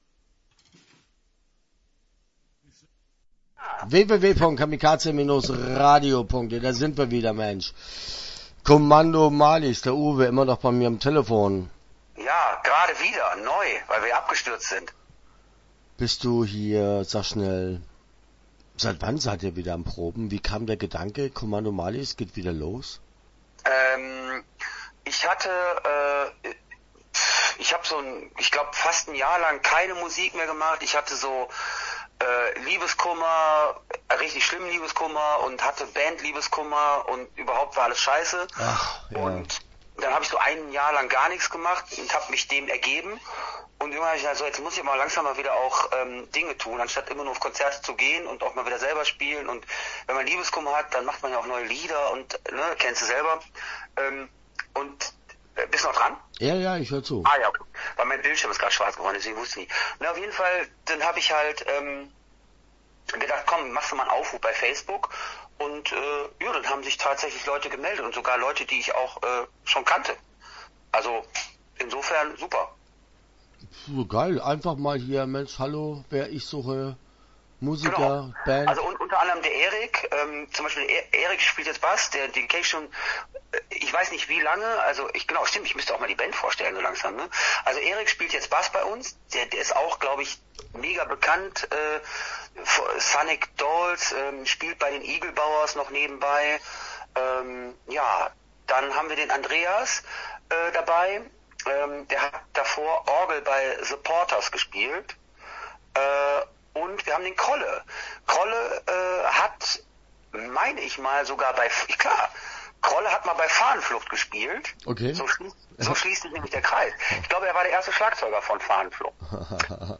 Kommando Marlies - Interview Teil 1 (12:39)